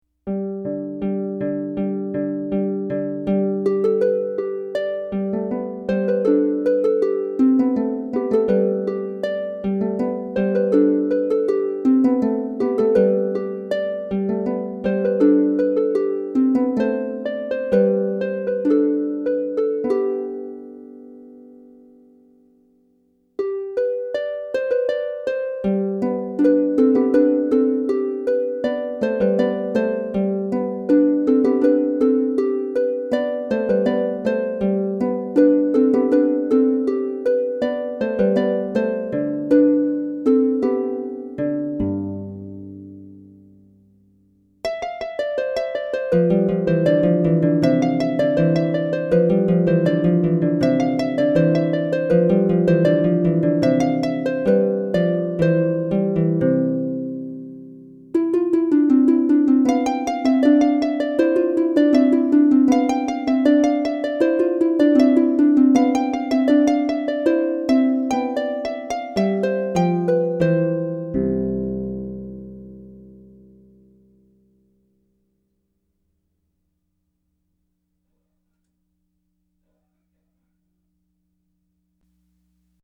for solo lever or pedal harp